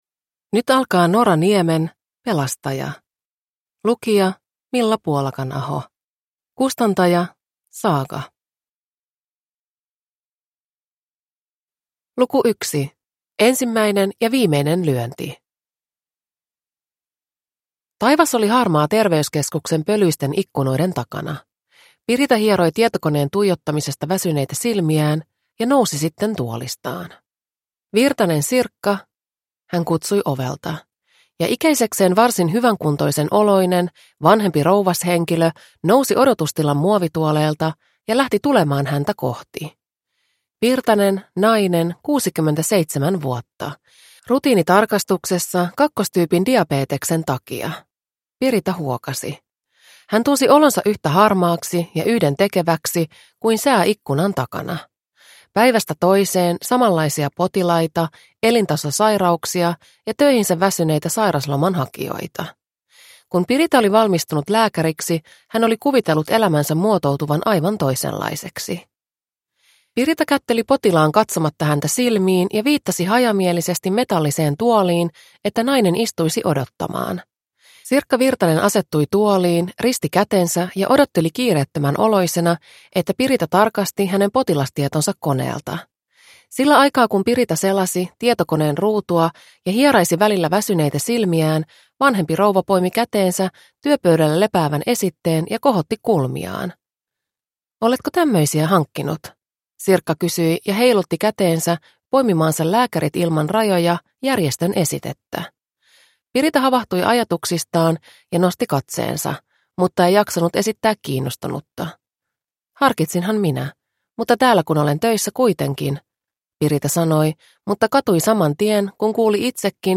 Pelastaja (ljudbok) av Nora Niemi